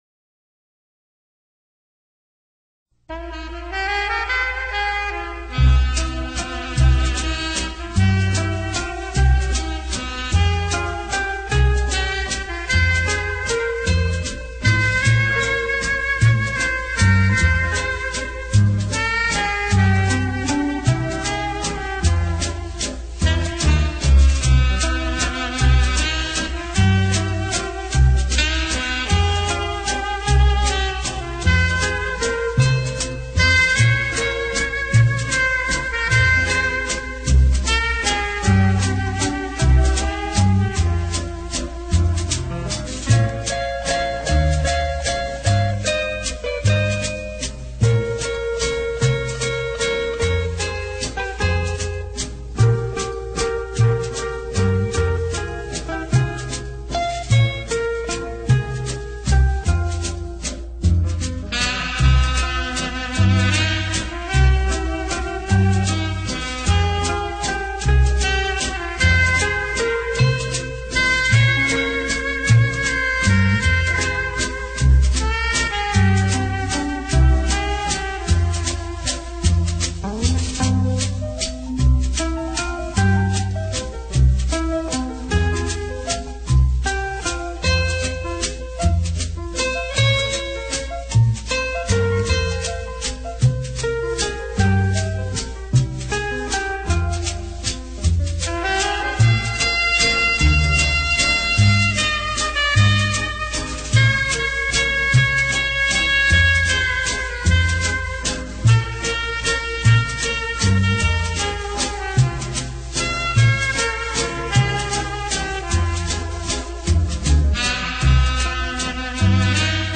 KFAqA6ZTXUd_cancion-de-aniversario.....vals...{olas-del-danubio}-para-quinceañosmatrimonios.mp3